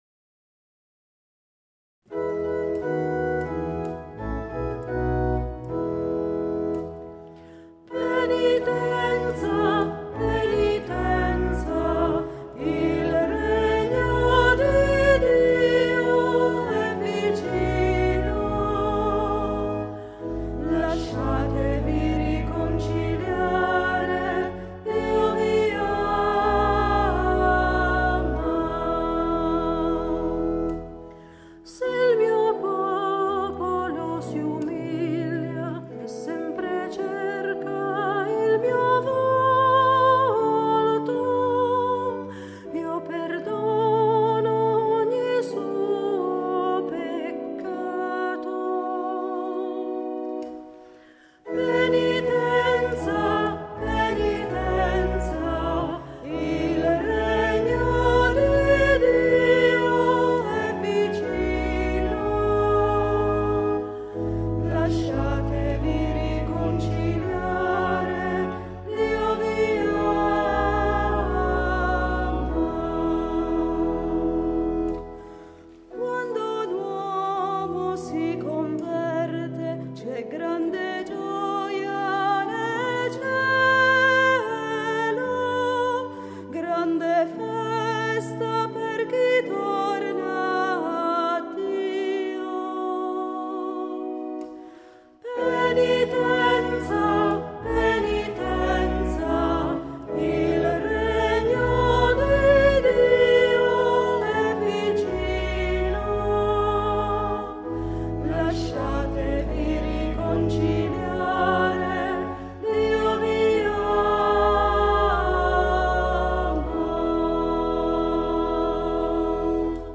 Un canto sul messaggio di Lourdes